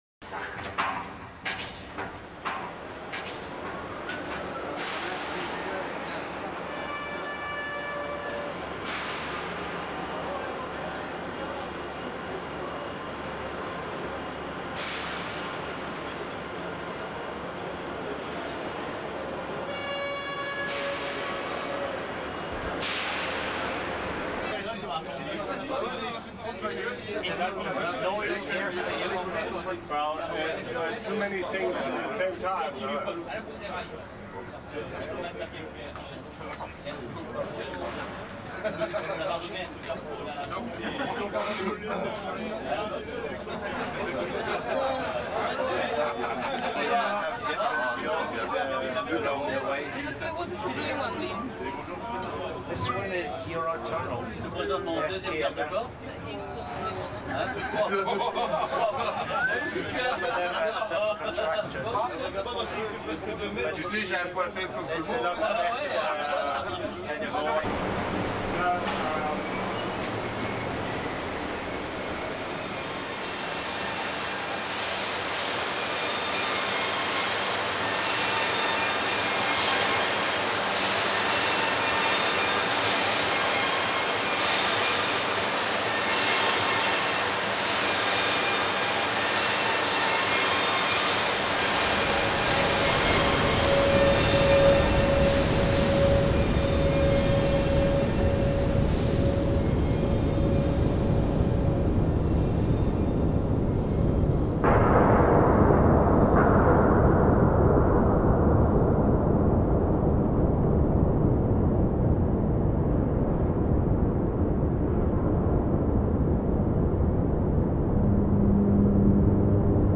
Audio-CD, Soundcollagen, Berlin 2008.